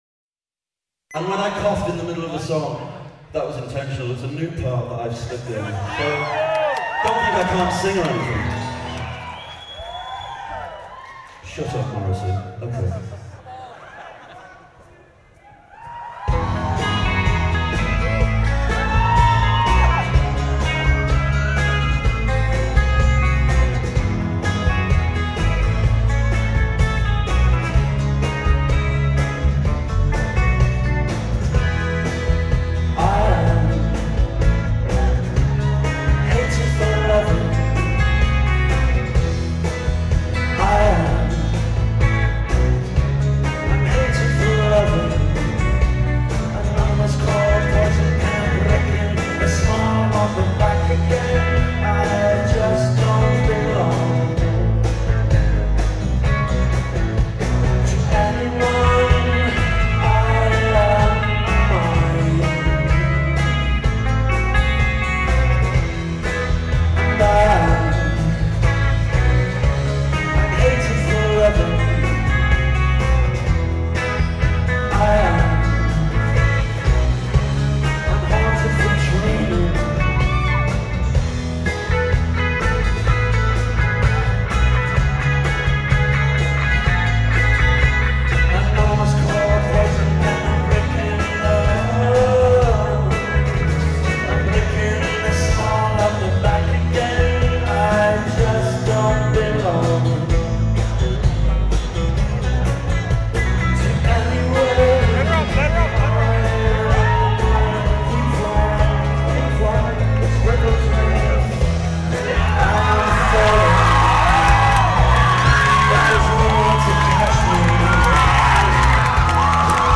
観客の感動がすごいでしょ。